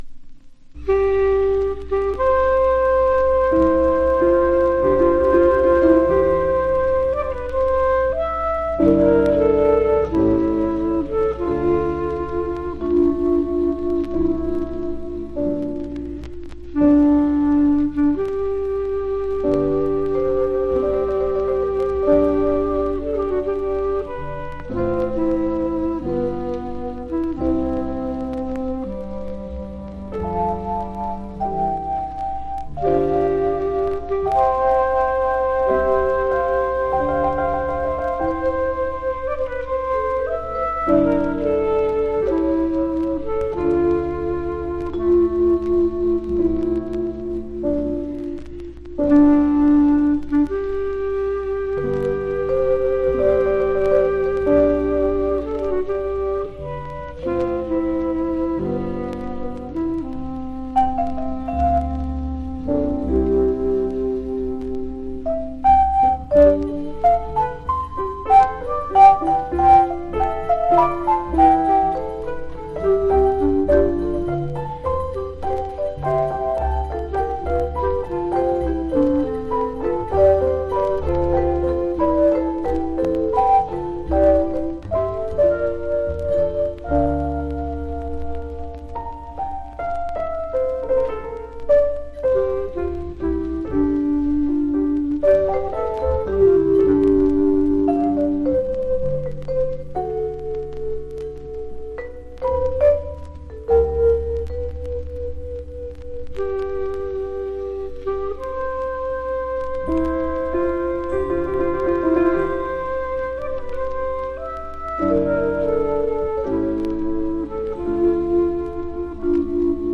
（プレス・小傷によりチリ、プチ音ある曲あり）
MONO
Genre US JAZZ